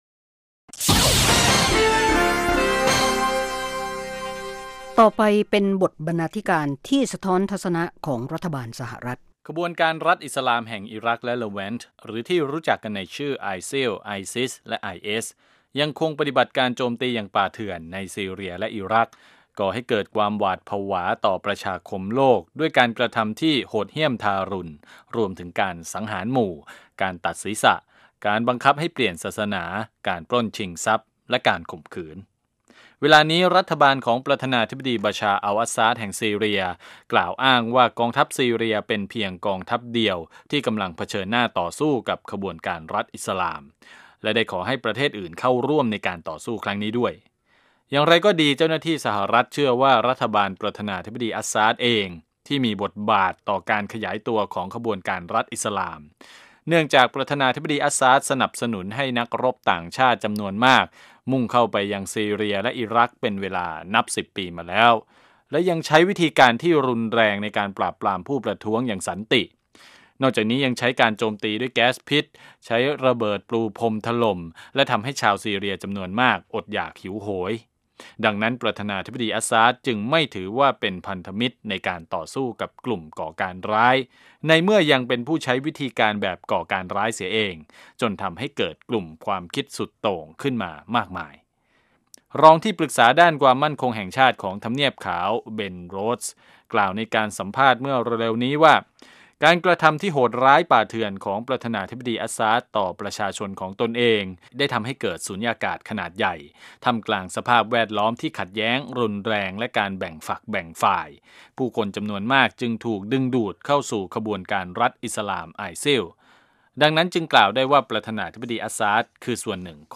วาไรตี้โชว์ ฟังสบายๆ สำหรับวันหยุดสุดสัปดาห์ เริ่มด้วยการประมวลข่าวในรอบสัปดาห์ รายงานเกี่ยวกับชุมชนไทยในอเมริกา หรือเรื่องน่ารู้ต่างๆ ส่งท้ายด้วยรายการบันเทิง วิจารณ์ภาพยนตร์และเพลง